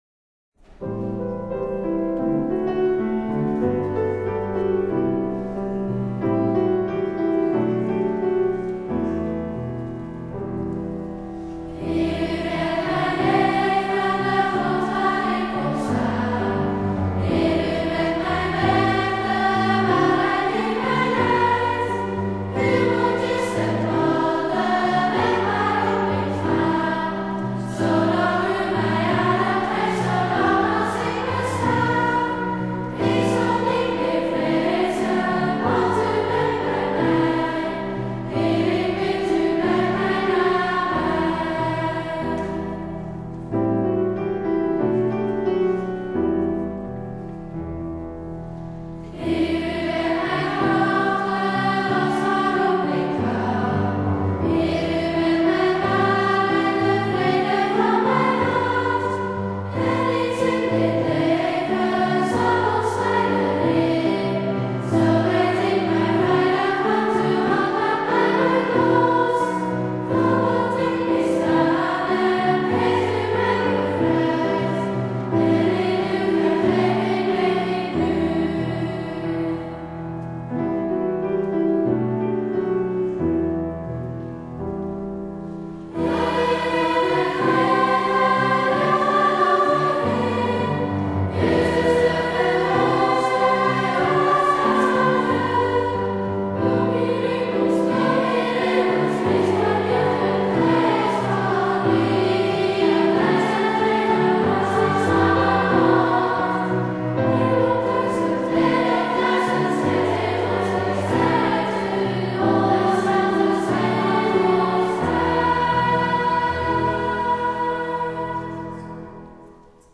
Ter herinnerring aan het 60 jarig jubileumconcert van leerlingen van het Driestarcollege in de grote kerk te Gouda
piano